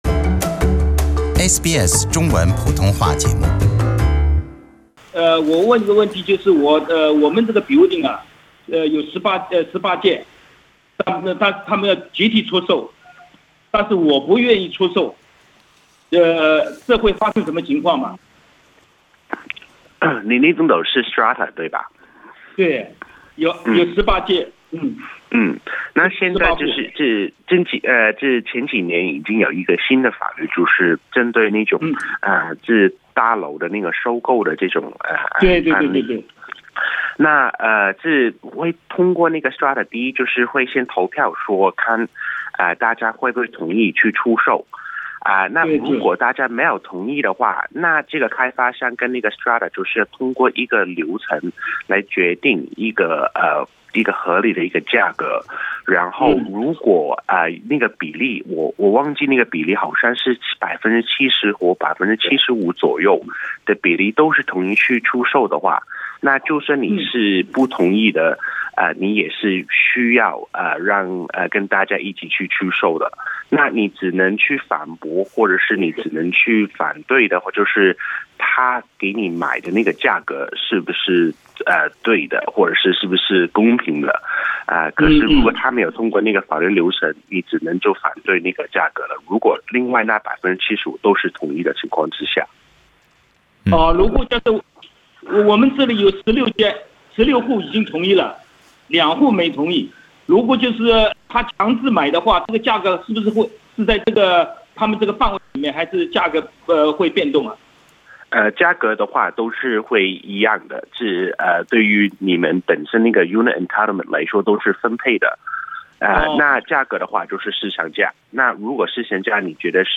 有听友拨打SBS普通话节目《现场说法》热线时表示，他所在Strata物业有18户，Strata计划出售整个物业，虽然作为业主的该听友反对，但在16户业主已经同意的前提下，Strata出售整个物业是合法的。